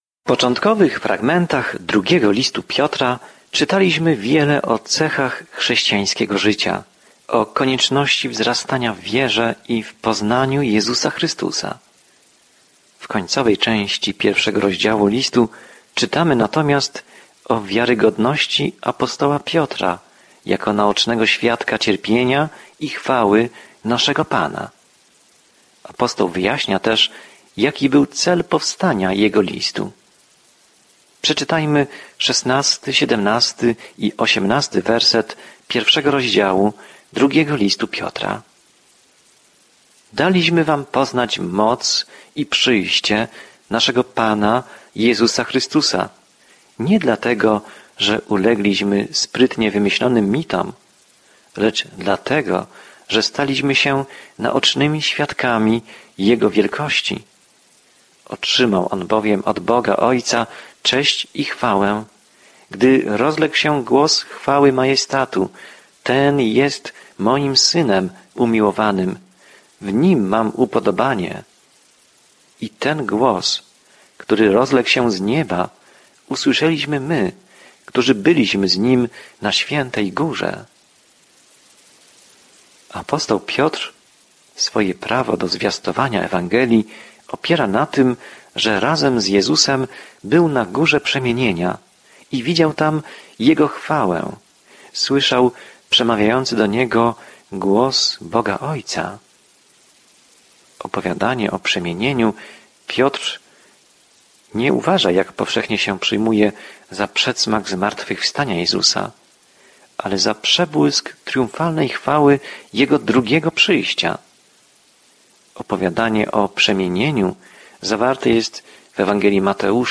Pismo Święte 2 Piotra 1:16-21 Dzień 3 Rozpocznij ten plan Dzień 5 O tym planie Drugi list Piotra jest w całości poświęcony łasce Bożej – jak nas zbawiła, jak nas utrzymuje i jak możemy w niej żyć – pomimo tego, co mówią fałszywi nauczyciele. Codzienna podróż przez 2 List Piotra, słuchanie studium audio i czytanie wybranych wersetów słowa Bożego.